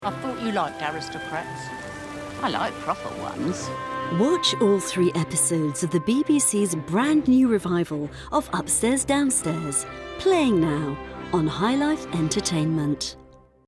Sprechprobe: Industrie (Muttersprache):
Very easy to work with, takes direction very well, adaptable, flexible, diverse, range of styles from Warm, Friendly, Sexy to Serious, Sad, Corporate.